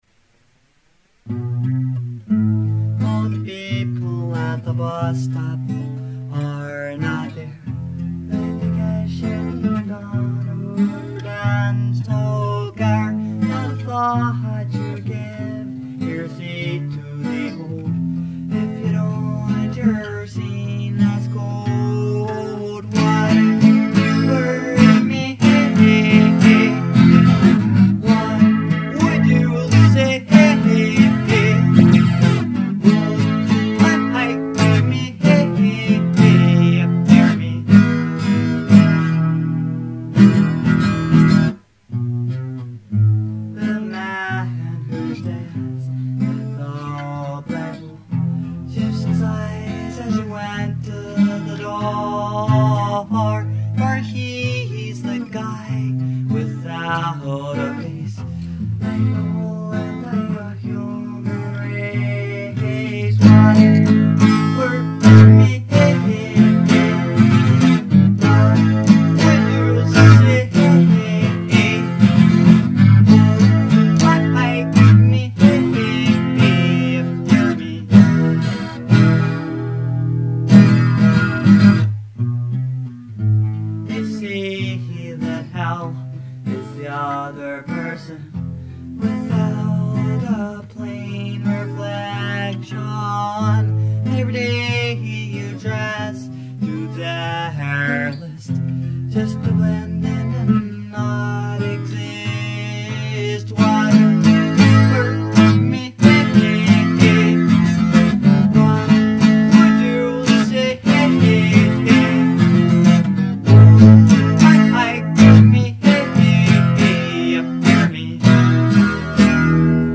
Music - I just bought a cheap microphone and have recorded two songs. They sound a bit ugly right now, but I will keep working on them Here are the songs: